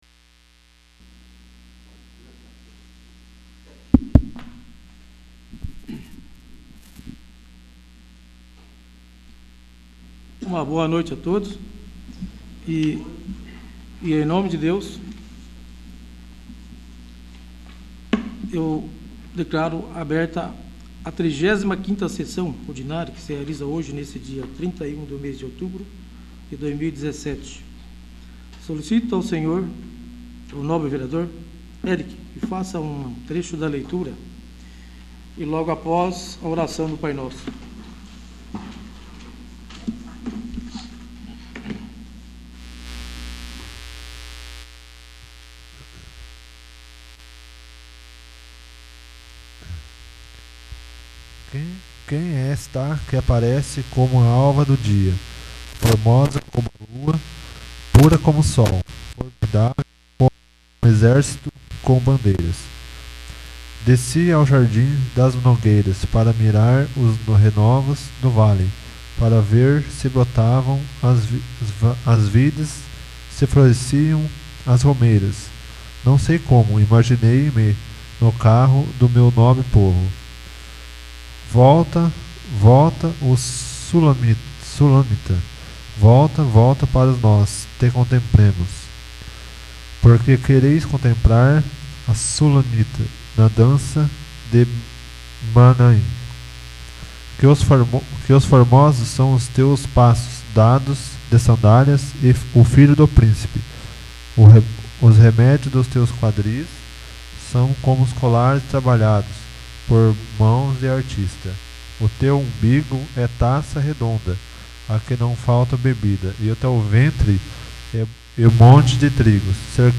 35º. Sessão Ordinária